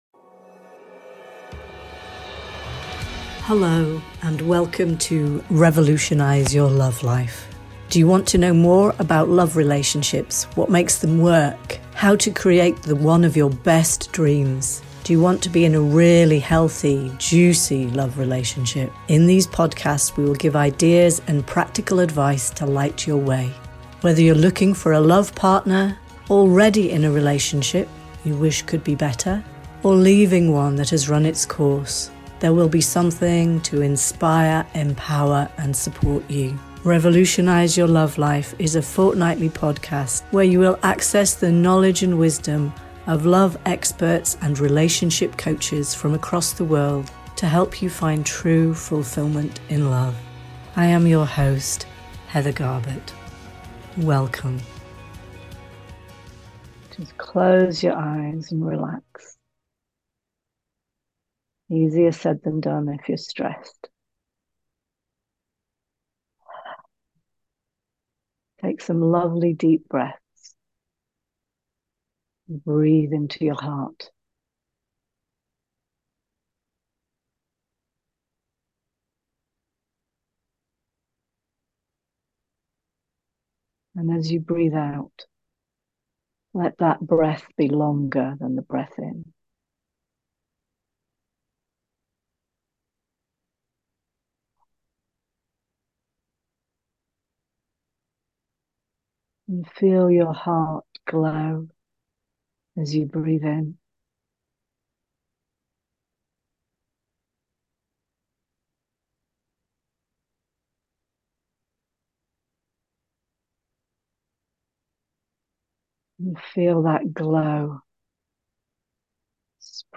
State of Overwhelm: Gentle & Deep Relaxation